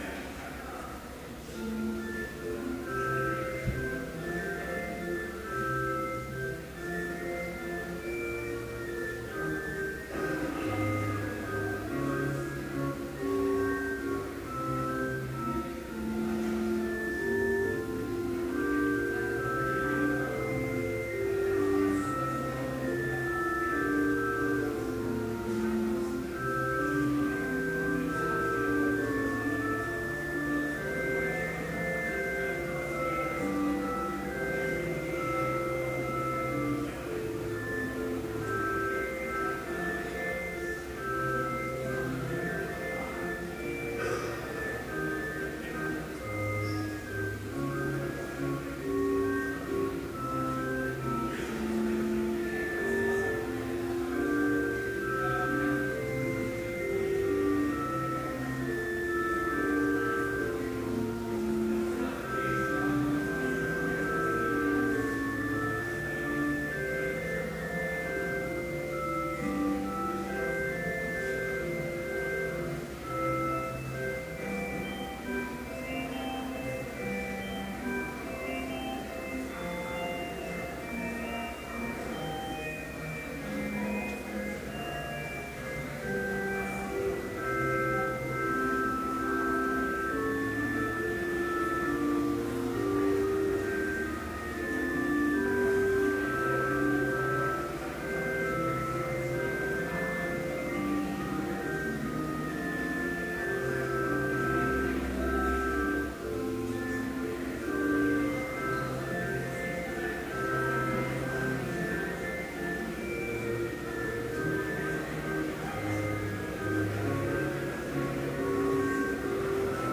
Complete service audio for Chapel - January 30, 2013
Listen Complete Service Audio file: Complete Service Sermon Only Audio file: Sermon Only Order of Service Prelude Hymn 226, vv. 1-5, By Grace I'm Saved Reading: I John 3:1-3 Homily Luther's Morning Prayer Hymn 226, vv. 9 & 10, By grace!